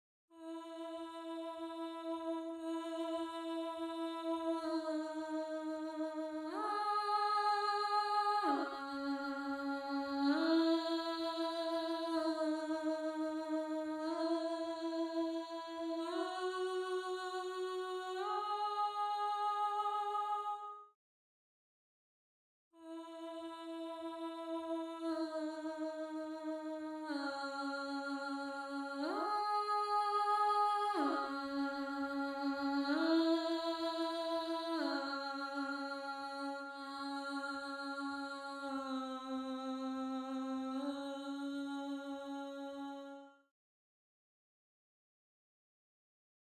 4. Voice 4 (Alto/Alto)
gallon-v8sp1-21-Alto_1.mp3